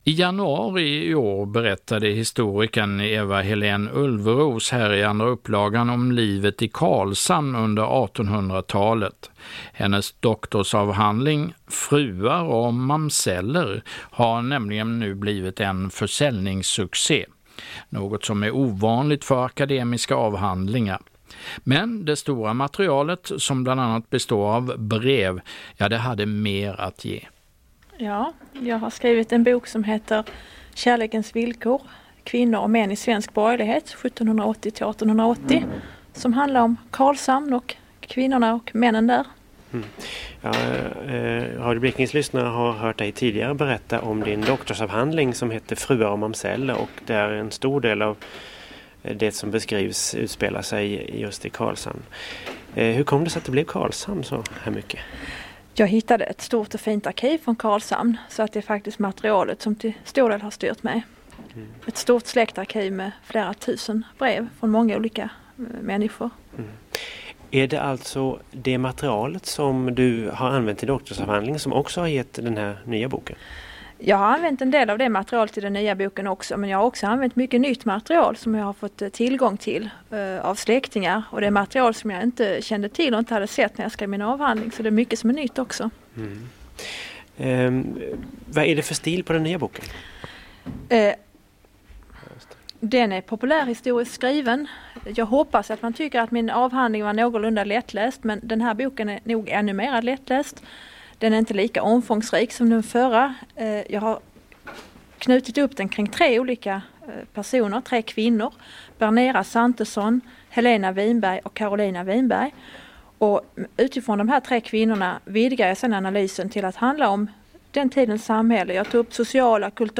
Kursen för allmänheten om Blekinges historia.